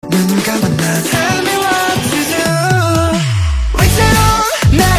SK_guitar_fx_pick_slide